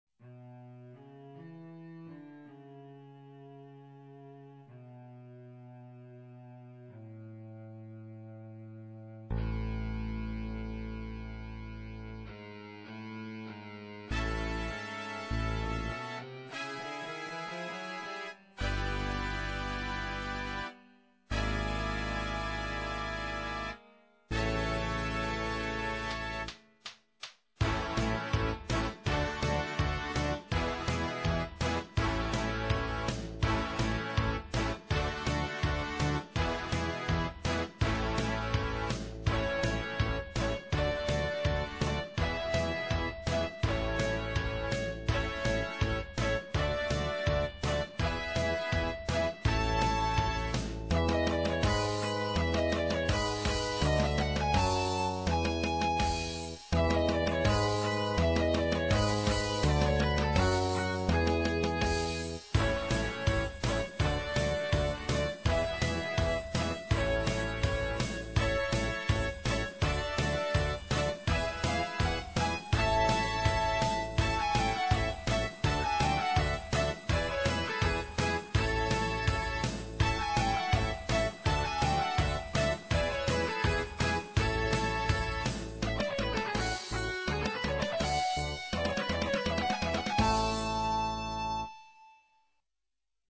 Everything is made in MIDI which gives a thinner sound.
[instr.]